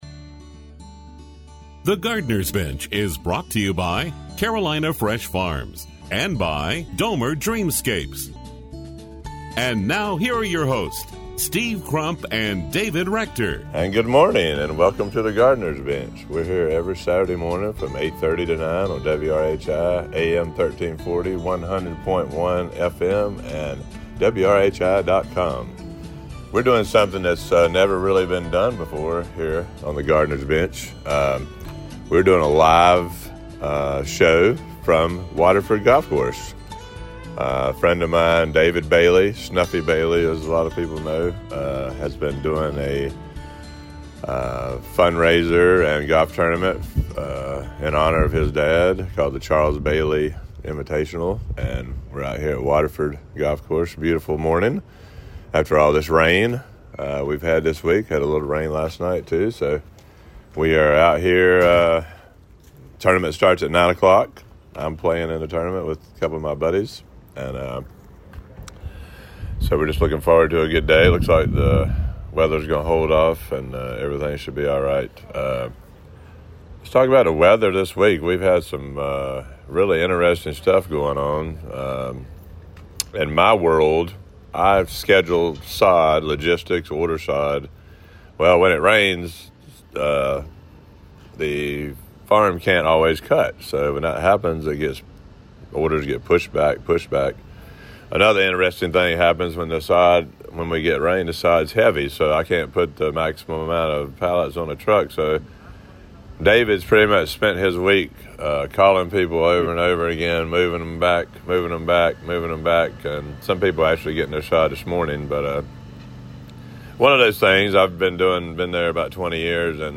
The Gardener’s Bench: 06/14/25 Live From Waterford Golf Course